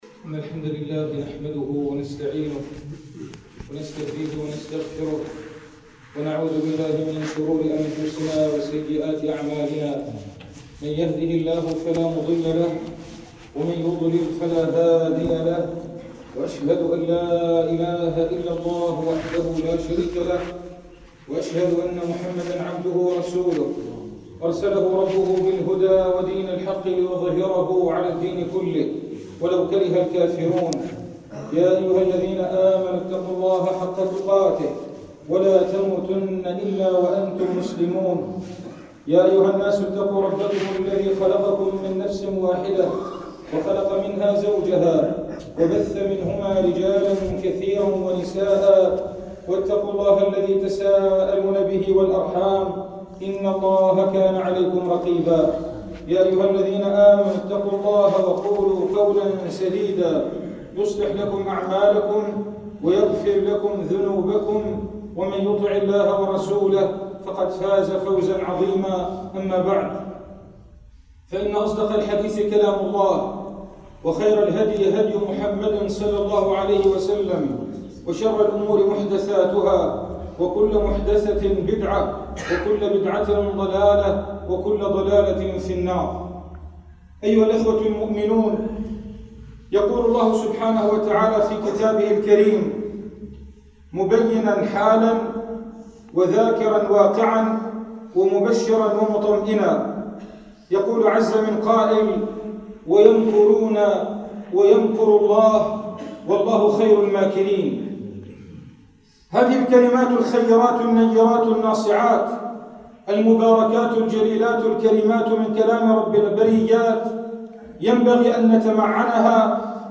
[خُطبة] ويمكرون ويمكر الله
المكان: مسجد القلمون البحري